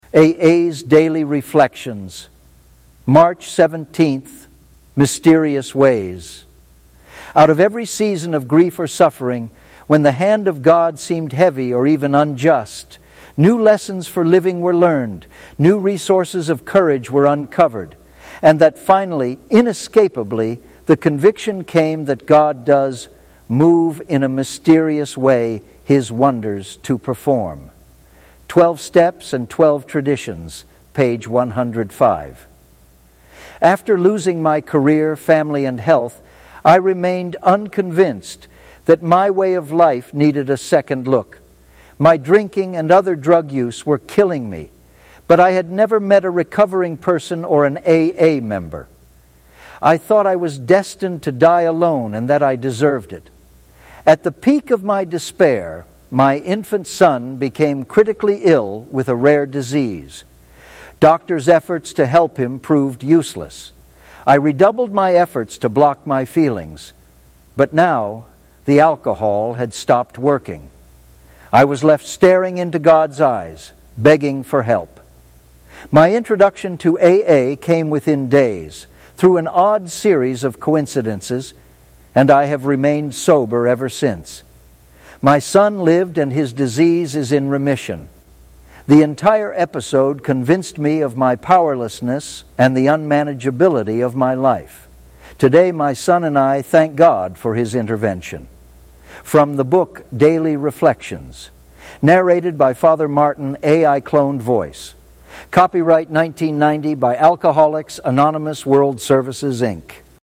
Daily Reflections